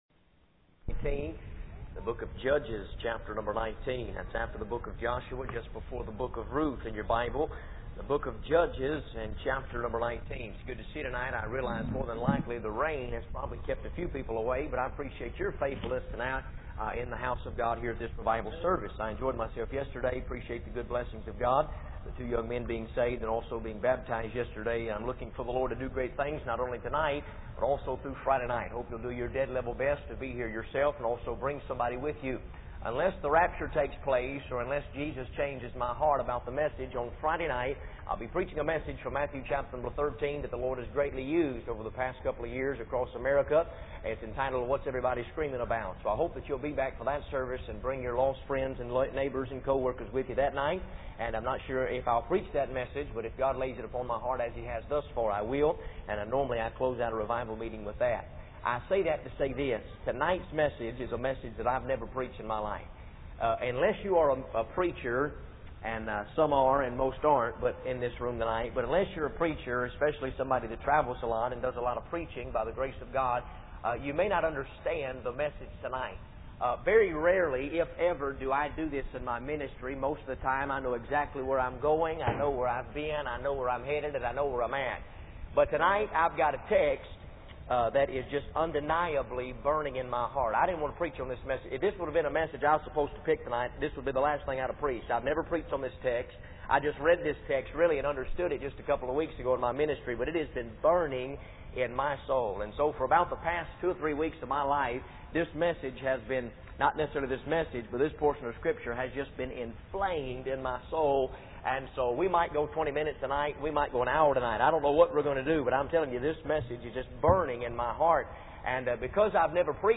In this sermon, the preacher discusses his experience at a Christ-centered addictions program in Rockford, Illinois.